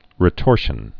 (rĭ-tôrshən)